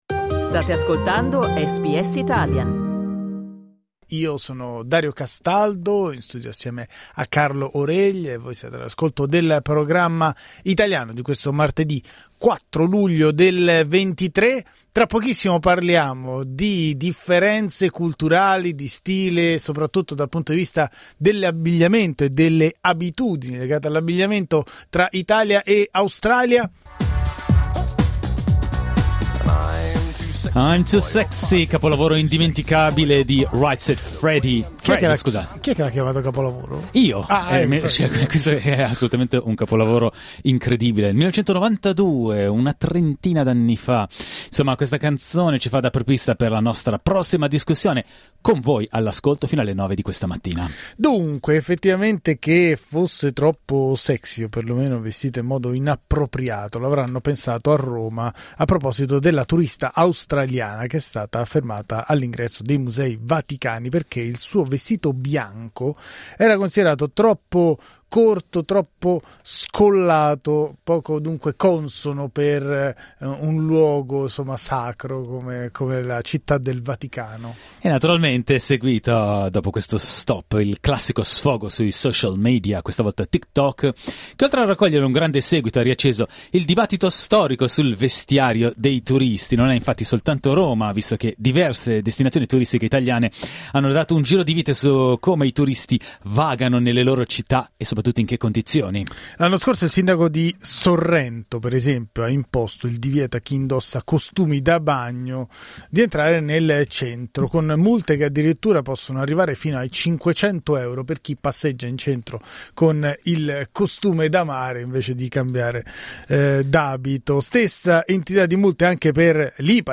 Da visite ai supermercati scalzi ai matrimoni ultra formali modello aristocrazia britannica, come ci vestiamo in Australia? Lo abbiamo chiesto ad ascoltatori e ascoltatrici.